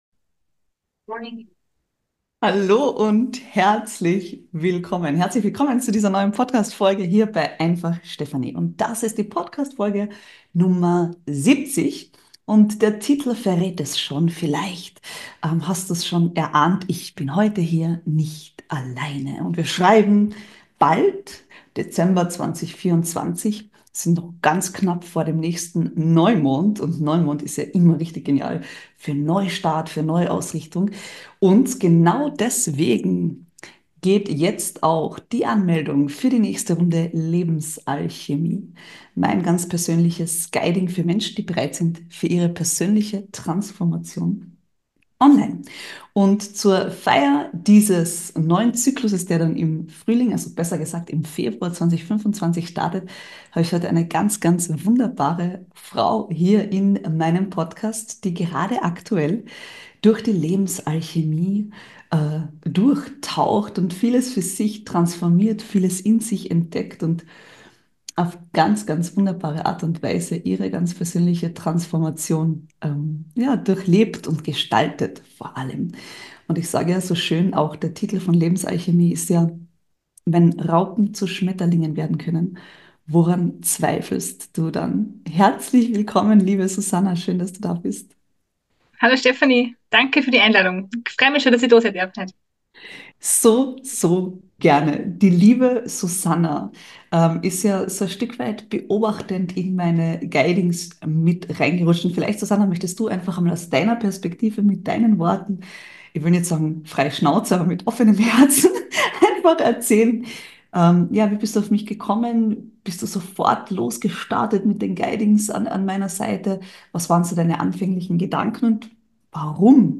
Ein herzoffener Talk, der Dir Insights Dank Insidern gibt, was meine Guidings bewegen können.